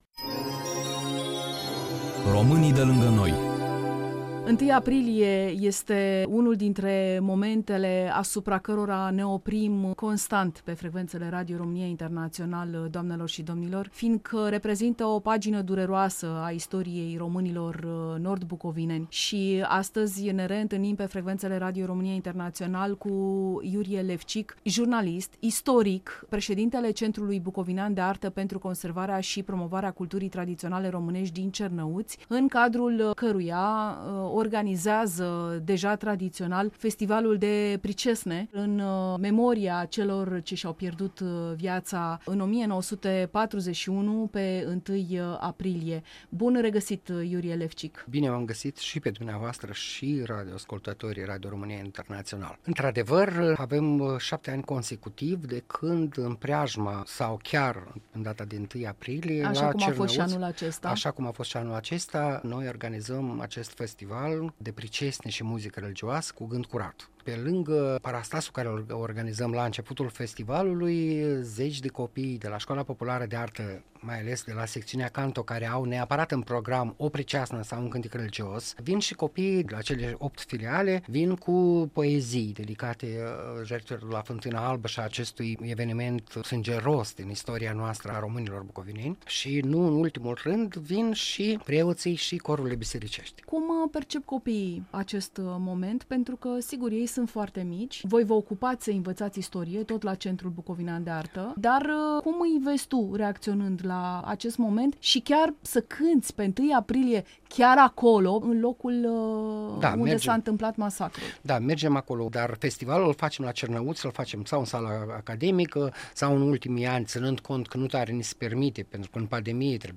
Festivalul de pricesne și muzică religioasă „Cu gând curat” (ediția a VII-a), organizat de Centrul bucovinean de artă pentru consevarea și promovarea culturii tradiționale românești din Cernăuţi în memoria victimelor de la Fântâna Albă – 1 aprilie 1941, Bucovina de Nord, teritoriu aflat astăzi în componența Ucrainei. Interviu